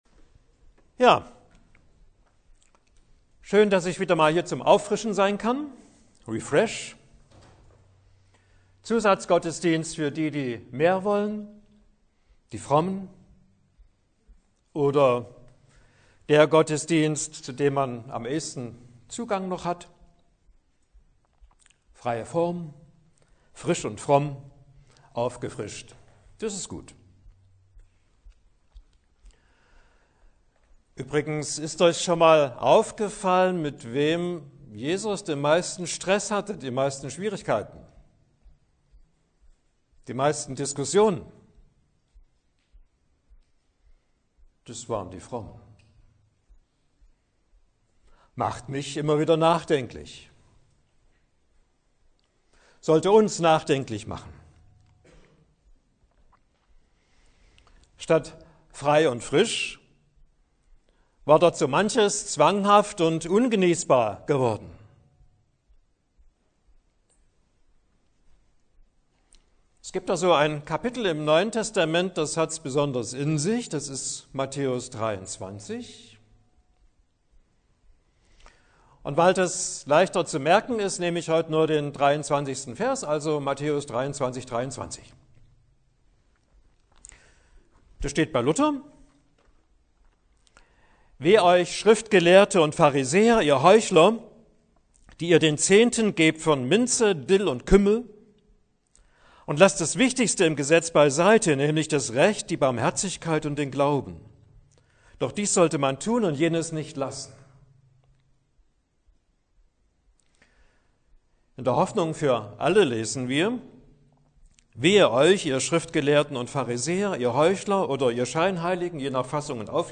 Unterscheidung – Glaube – Barmherzigkeit Predigt zum refresh-Gottesdienst. 40 Minuten.